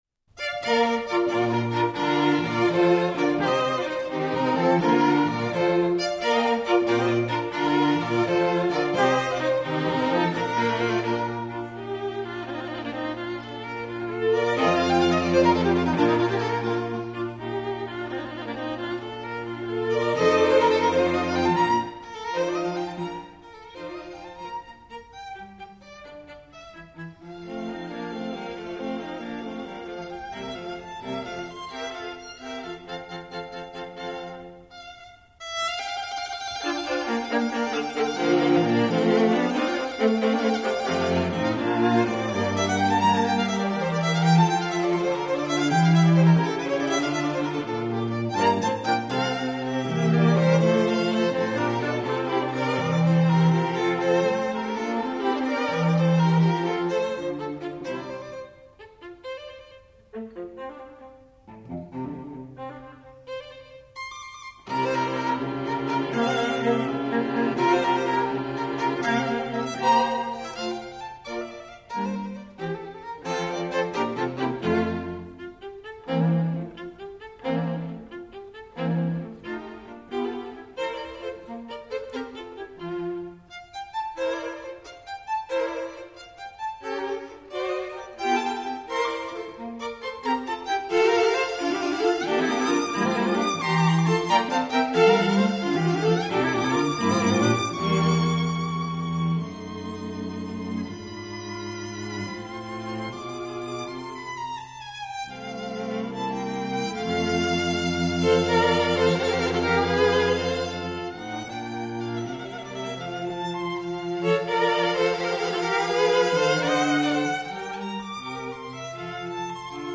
String Quartet in B flat major "The Hunt"
Allegro vivace assai